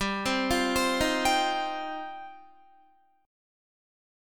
G7sus4#5 Chord